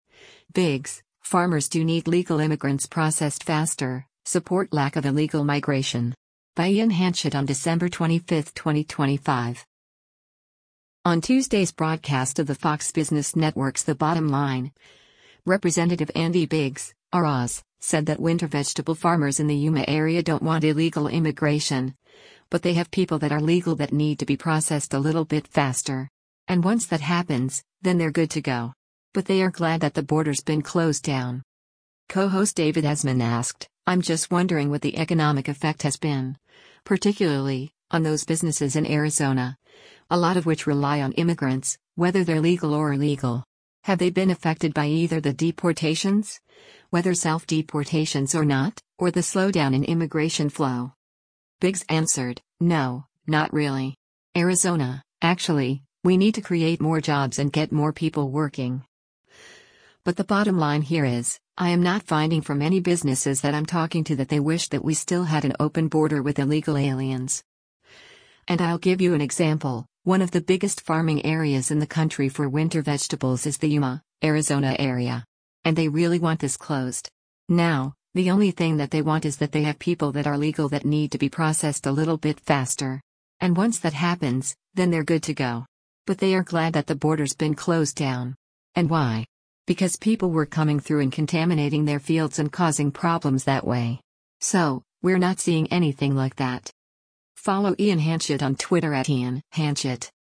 On Tuesday’s broadcast of the Fox Business Network’s “The Bottom Line,” Rep. Andy Biggs (R-AZ) said that winter vegetable farmers in the Yuma area don’t want illegal immigration, but “they have people that are legal that need to be processed a little bit faster. And once that happens, then they’re good to go. But they are glad that the border’s been closed down.”